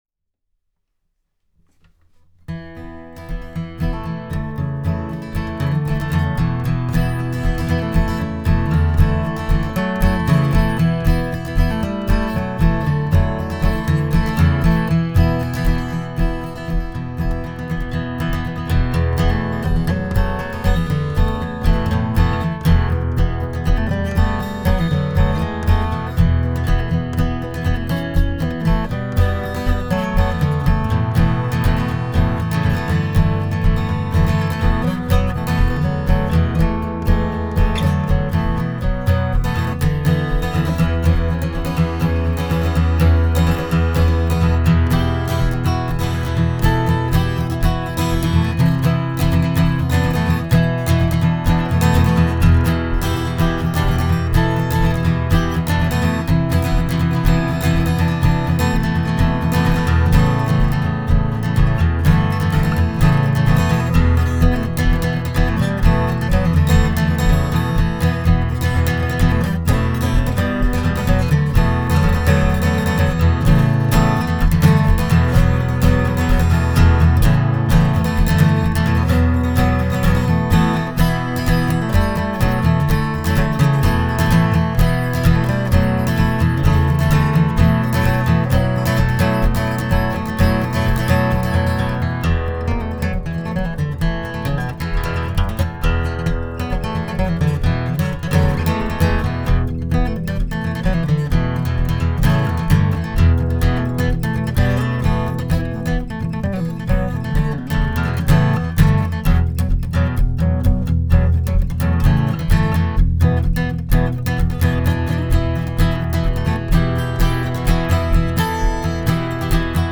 Bändchenmikrofon / Achter Charakteristik Aufnahme Tipps
Vollmassiv, Fichtendecke, back and sides Mahagony. Ich packe mal das Stück mit beiden Mic´s und ein bisschen Bearbeitung dran. Das Zweite war wie gesagt das Beyerdynamic M201TG. Die Kombi Bändchen 12. Bund/ M201 am Steg gefiel mir auch besser als M201 am 12. und Bändchen am Steg.